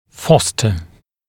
[‘fɔstə][‘фостэ]поощрять, побуждать, стимулировать